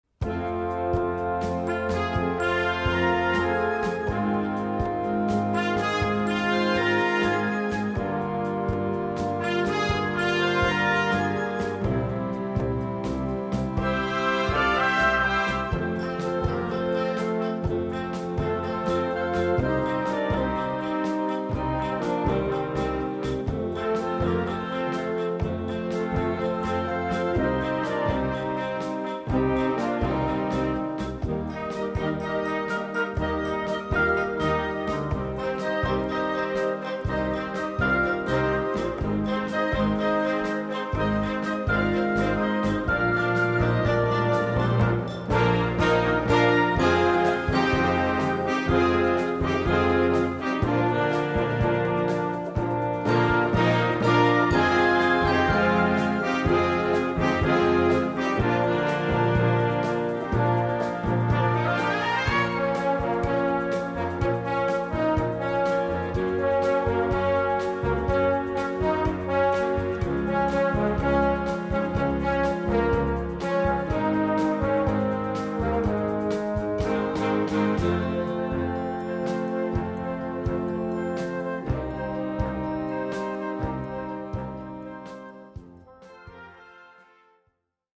Besetzung: Blasorchester
schönen, langsamen Song für Blasorchester